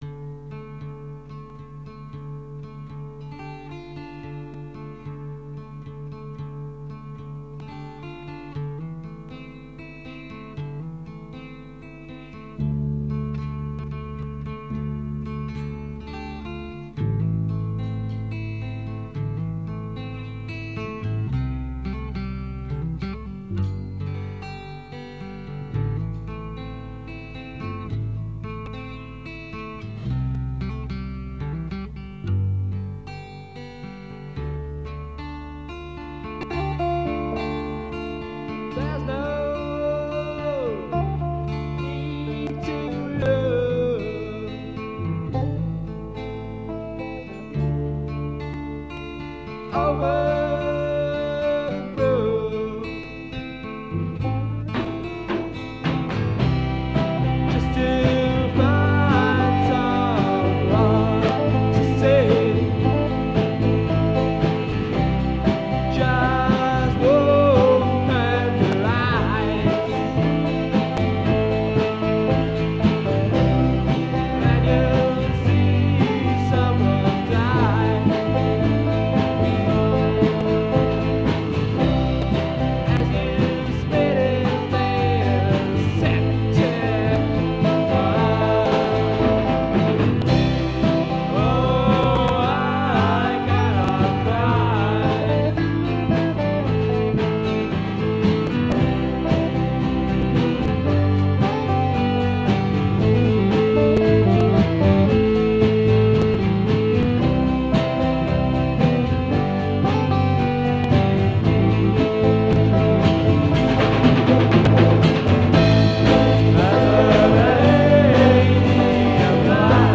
Proberaum Demos
Recorded at Don Bosco Turmherberge 1977